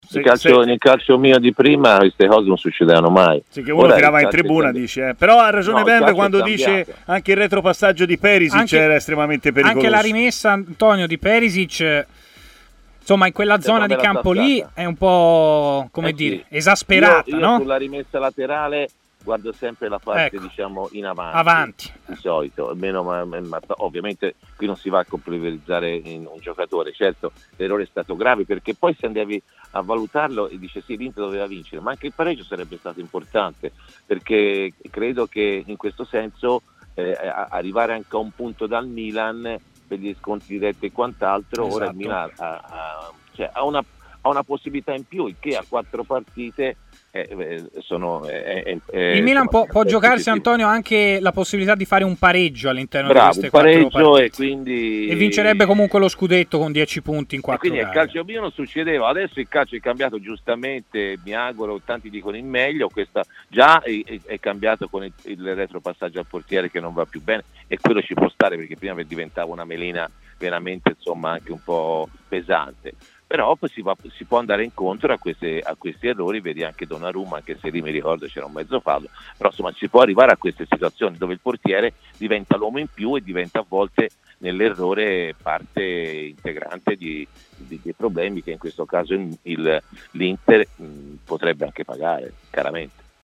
Raggiunto telefonicamente dai microfoni di TMW Radio, l'ex centrocampista Antonio Di Gennaro ha commentato brevemente la sconfitta di mercoledì sera dell'Inter: "Nel calcio dei miei tempi errori come quello di Radu non capitavano mai. Anche un pareggio sarebbe stato importante, per rimanere a -1 dal Milan e non concedergli quel pareggio disponibile che ora hanno".